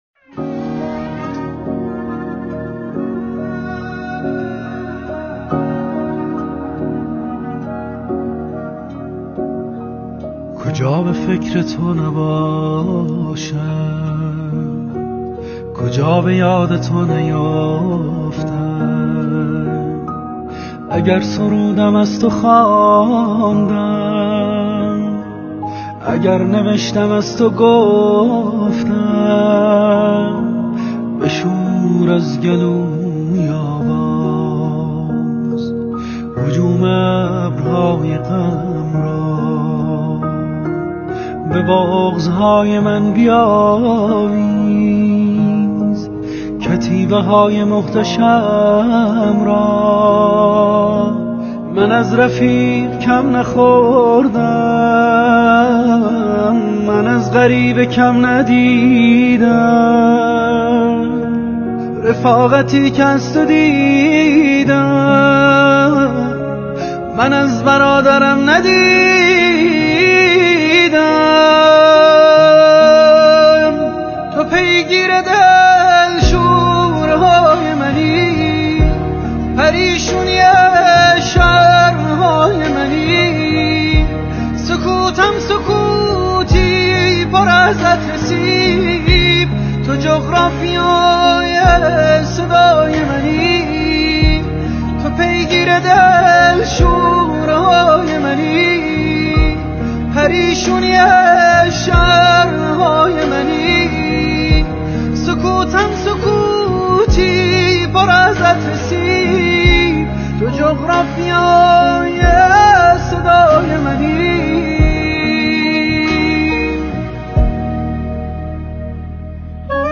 آهنگ تیتراژ برنامه